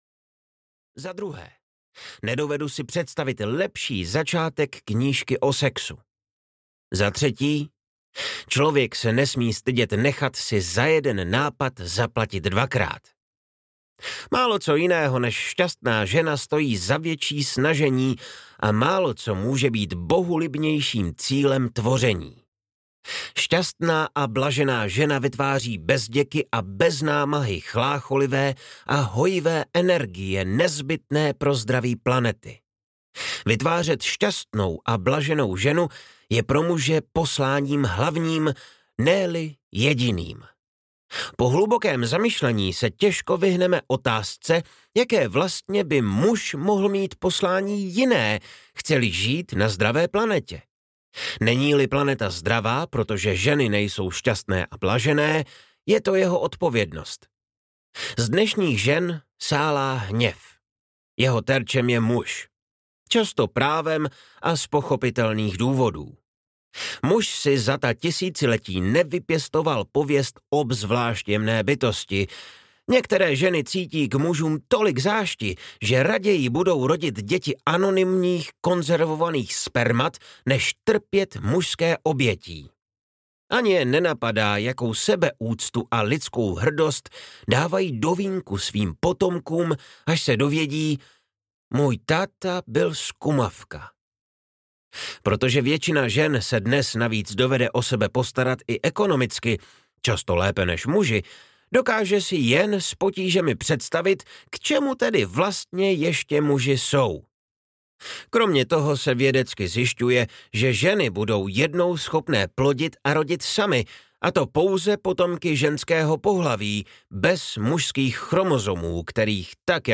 TAO sexu – Jak udržovat ženu v blahu a zpomalit stárnutí audiokniha
Ukázka z knihy